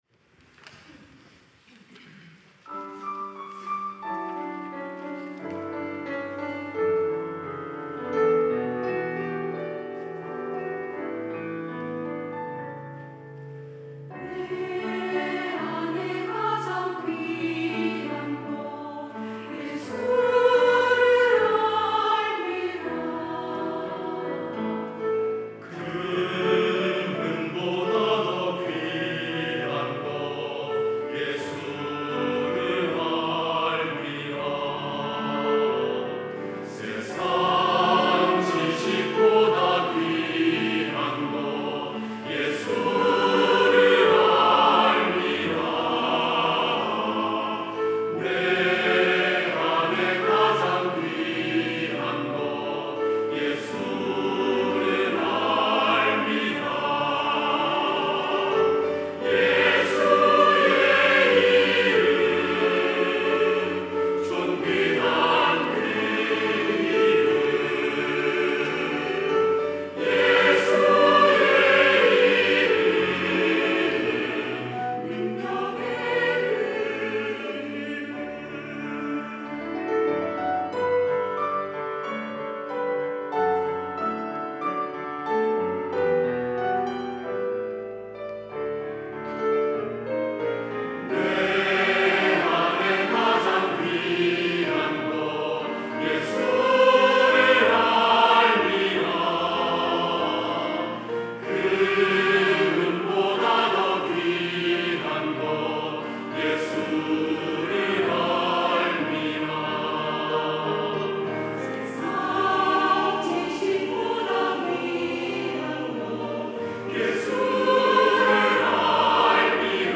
할렐루야(주일2부) - 내 안에 가장 귀한 것
찬양대 할렐루야